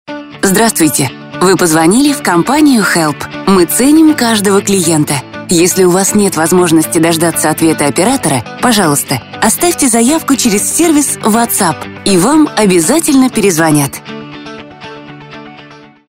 Голосовое приветствие IVR 4 – http___zvuk